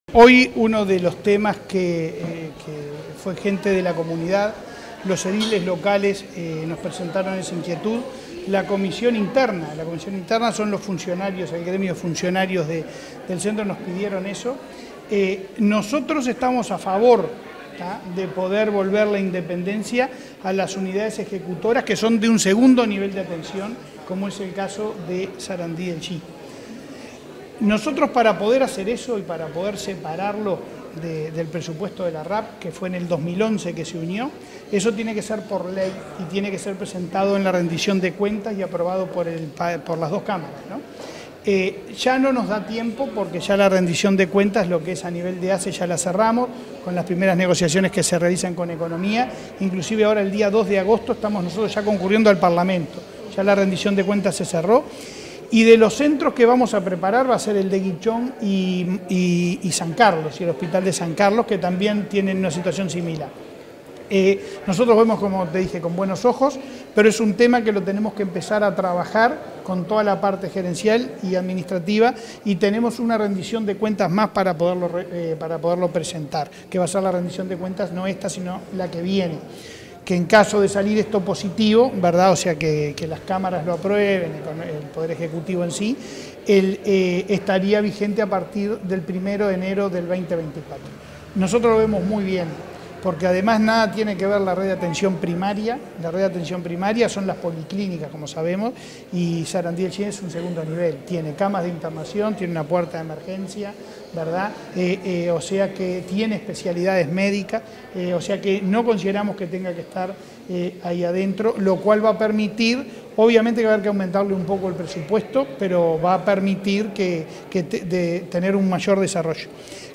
Declaraciones a la prensa del presidente de ASSE, Leonardo Cipriani
Tras recorrer las intalaciones del hospital de Durazno, este 26 de julio, el presidente de la Administración de los Servicios de Salud del Estado